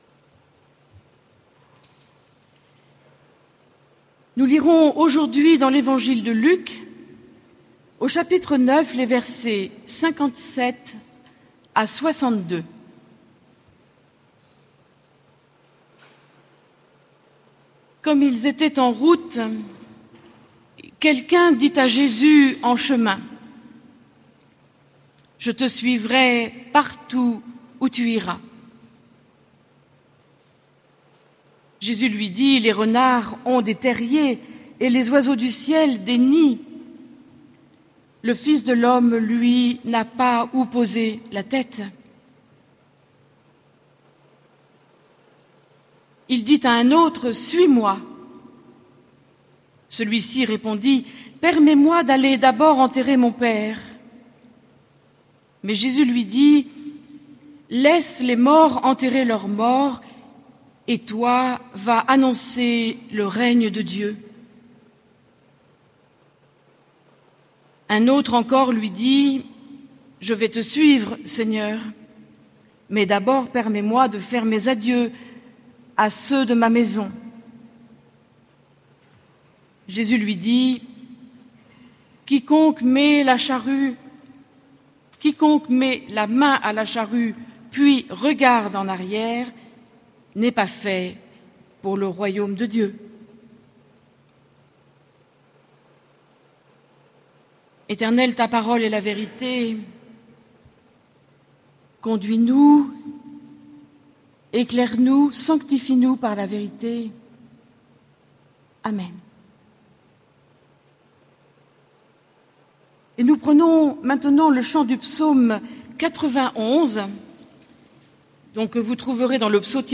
Culte de commémoration de la Saint Barthélemy - Oratoire du Louvre